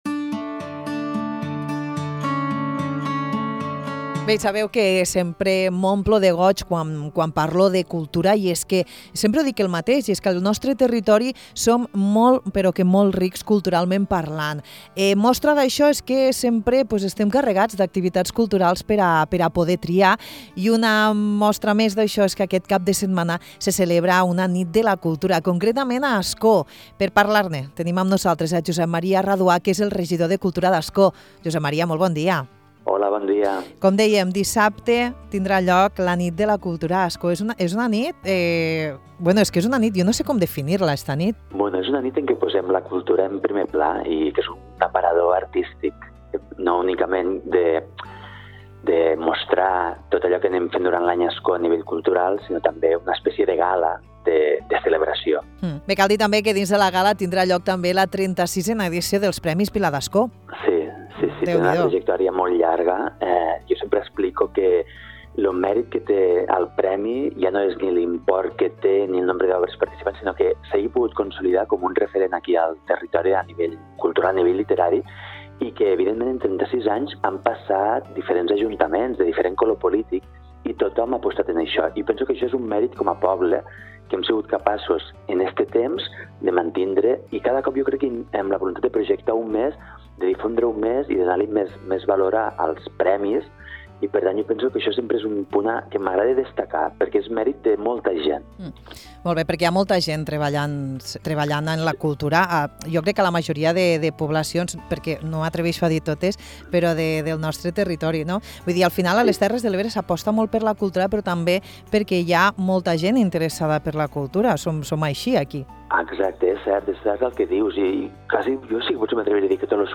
Dissabte 11 de maig, al Casal Municipal d’Ascó, torna la Nit de la Cultura que arriba a la seva cinquena edició. Josep Maria Raduà, regidor de cultura, ens parla d’aquesta gala que estarà oberta a tothom i serà a les 19 h.